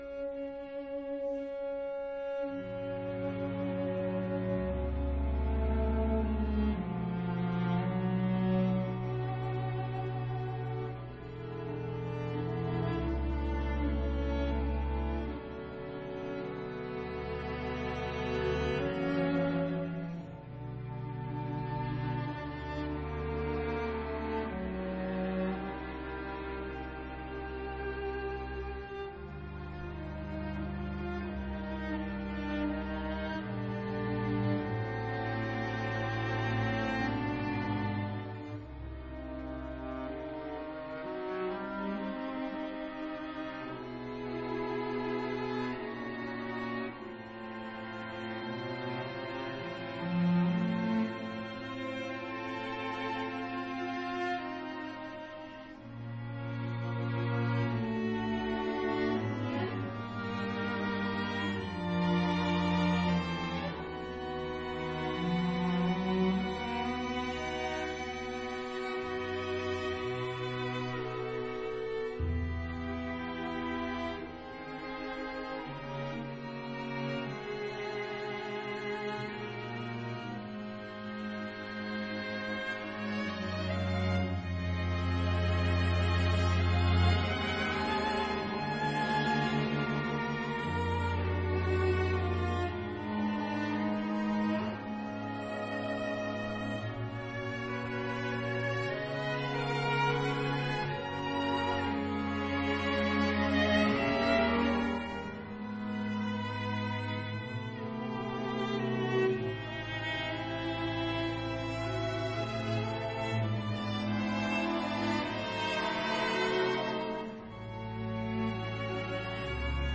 被改編為弦樂七重奏後，竟然更加容易聆聽。
有感而發！所以，整個曲子，有些部分像是送葬般哀傷，甚至無望。
這個七重奏版（試聽二），讓台下的聽眾也迷了，
樂曲結束時，久久才想到要鼓掌⋯⋯